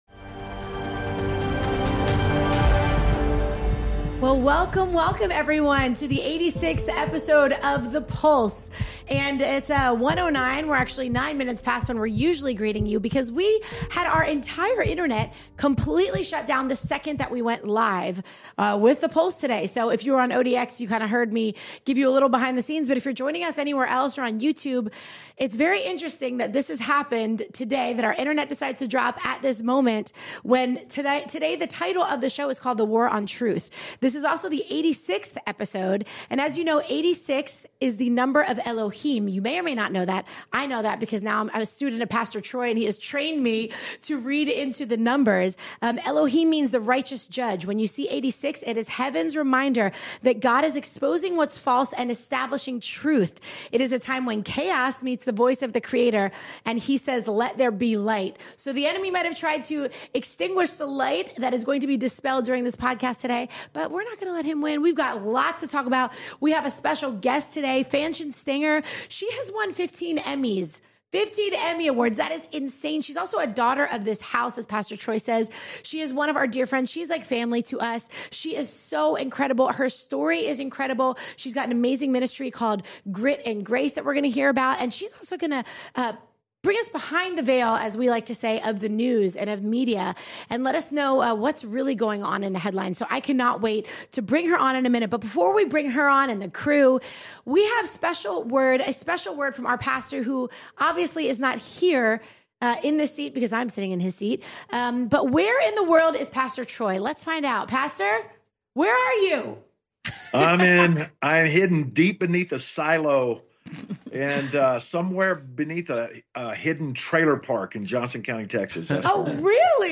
From fake news to truth censorship, discover how the media spins narratives. This is sure to be an eye-opening, faith-filled conversation about standing for truth, discerning deception, and seeing the story through God’s lens.